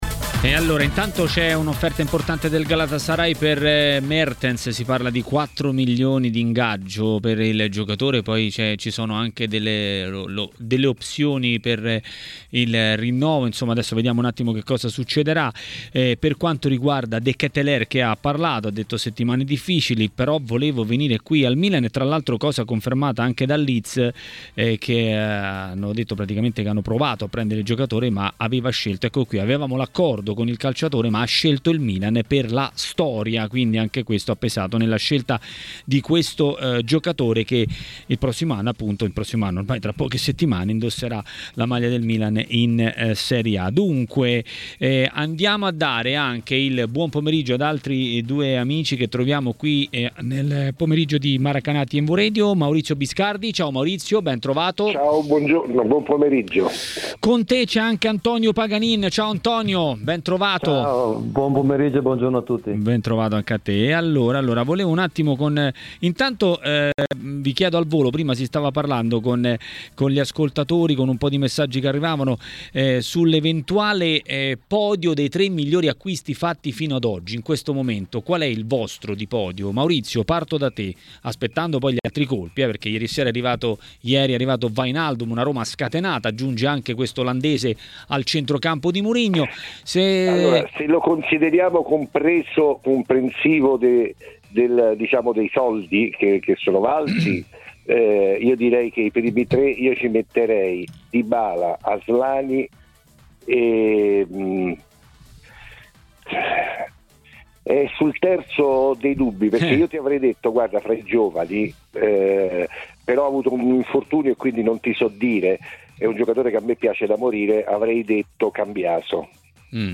Per parlare di Inter e non solo a TMW Radio, durante Maracanà, è intervenuto l'ex calciatore Antonio Paganin.